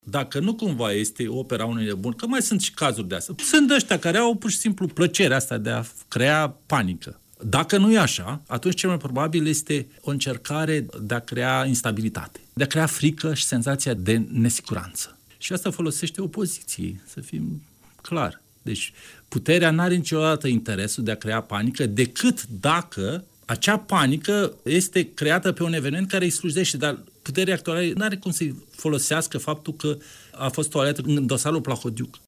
a spus, în emisiunea „Imperativ”, de la Radio Iași, că un astfel de incident poate fi comis de o persoană care dorește să provoace panică și nesiguranță.